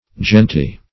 genty - definition of genty - synonyms, pronunciation, spelling from Free Dictionary Search Result for " genty" : The Collaborative International Dictionary of English v.0.48: Genty \Gen"ty\, a. [From F. gentil.